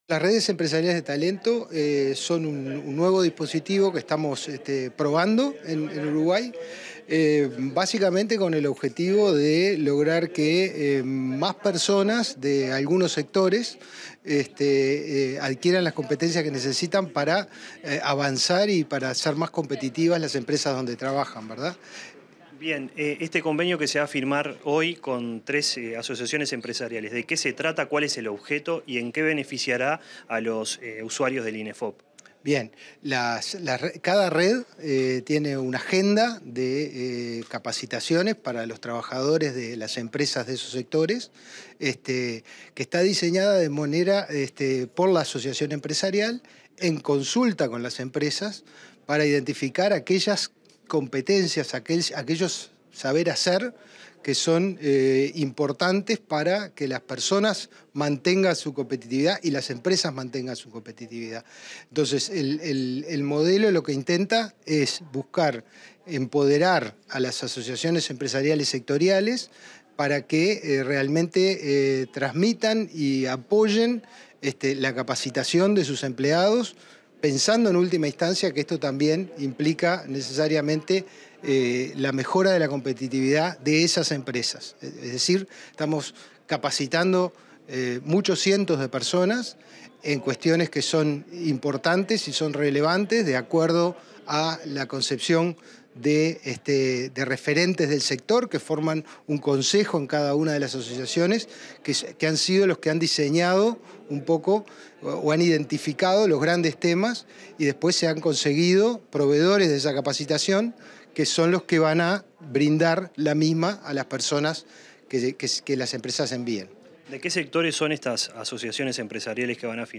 Entrevista al director general de Inefop, Pablo Darscht